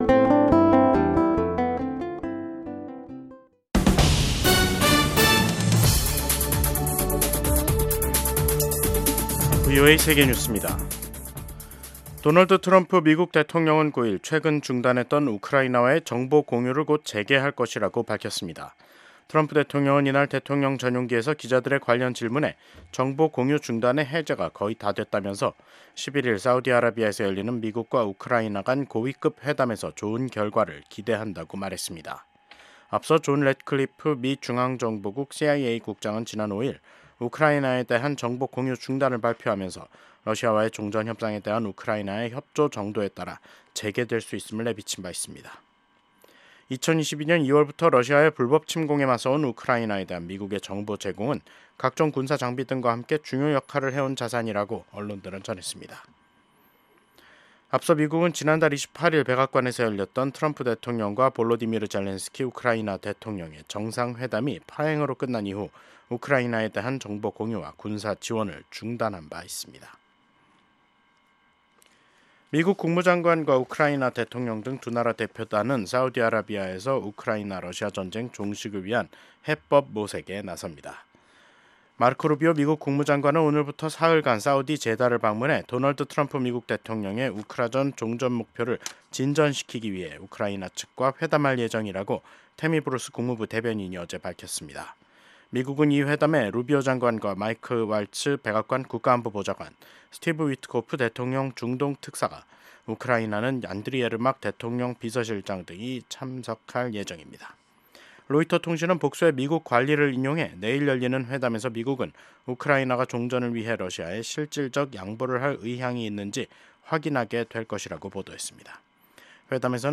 VOA 한국어 간판 뉴스 프로그램 '뉴스 투데이', 2025년 3월 10일 2부 방송입니다. 북한이 오늘 근거리 탄도미사일(CRBM)로 추정되는 수발의 발사체를 서해상으로 쐈습니다. 미국의 중국 전문가들과 전직 관리들이 트럼프 행정부가 한국, 일본과 협력해 북러 군사협력을 막기 위해 중국이 건설적 역할을 할 것을 압박해야 한다고 제안했습니다. 북러 군사협력이 날이 갈수록 심화되는 가운데 북한과 러시아 접경 지역에서 열차 움직임이 크게 증가하고 있습니다.